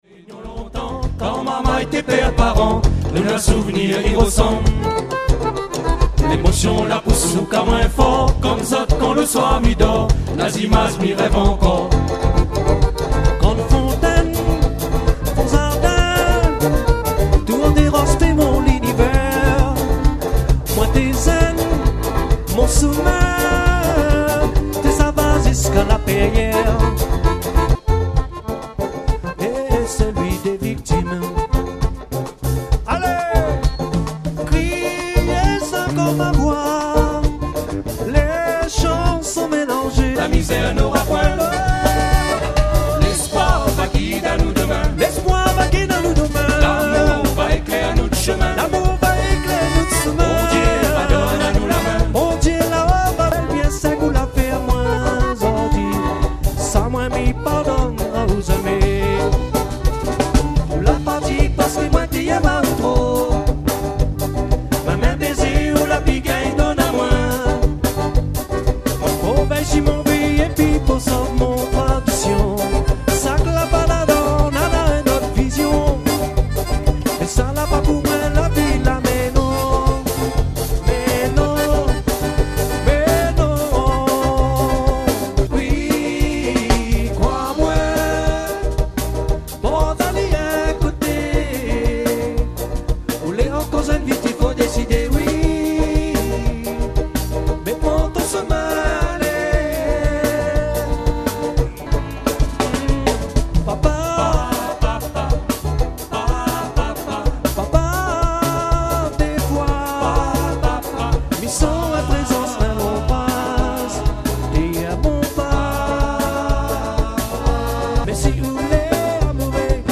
Voix Lead et Banjo
Accordéon et Choeurs
Batterie et Choeurs
Basse acoustique et Choeurs
Le 22 août 2009 - Salle Guy Agenor - Plaine des Palmistes